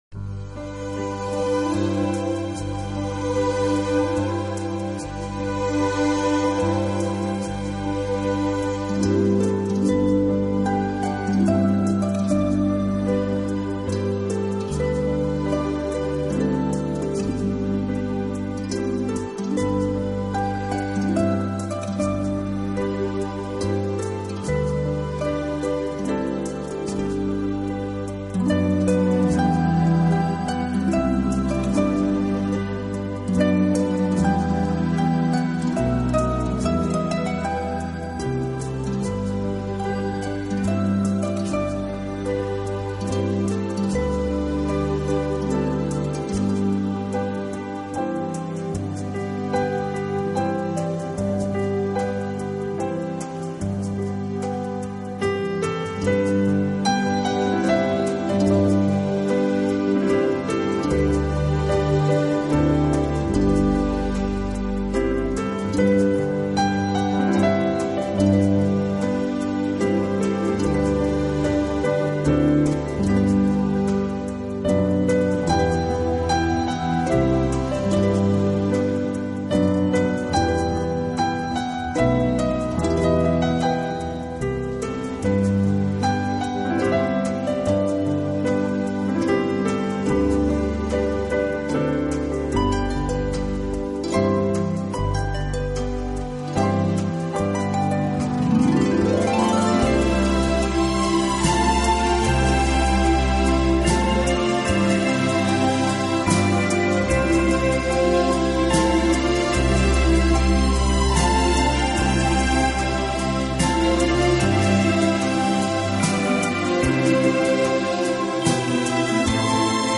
Acoustic（原音）是指原声乐器弹出的自然琴声（原音），制作录音绝对不含味精，乐器
本套CD音乐之音源采用当今世界DVD音源制作最高标准：96Khz/24Bit取样录制，其音源所
确的结像力，极宽的动态范围，更逼真的现场效果，更细腻纯正的音质，彻底超越传统CD
空灵而轻巧，抚慰人心的流动自如和清越婉转——竖琴，好像一个未涉世事的少女，笑声
纯净动人，又宛如一位多情婉约的古典美人，时而温存时而神秘。美不胜收的感觉来自--
--纯乐竖琴。